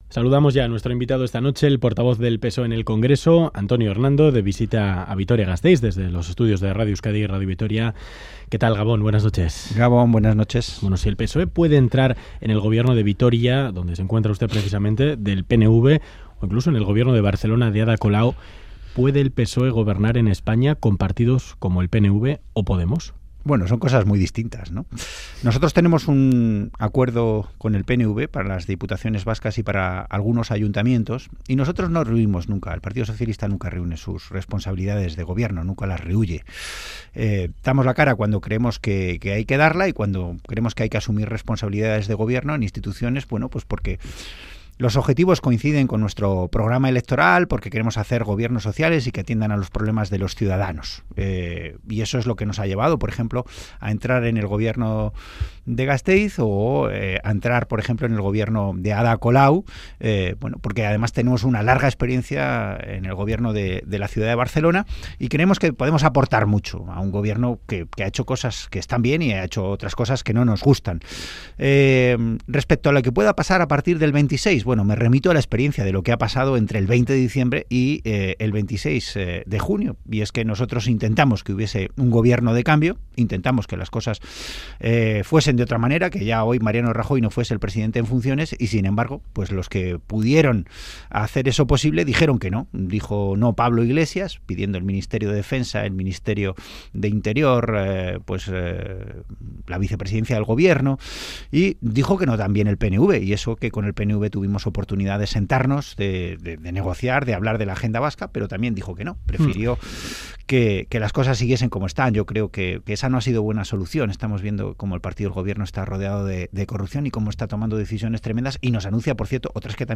El Portavoz del PSOE en el Congreso Antonio Hernando ha asegurado en Ganbara que la prioridad tras el 26J será pactar un gobierno de cambio.